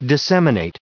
242_disseminate.ogg